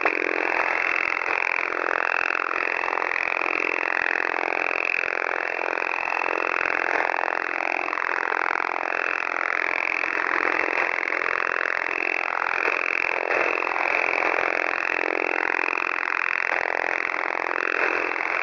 Over the horizon radar heard on 5362 kHz USB – 23 October 2020 about 18:11 UTC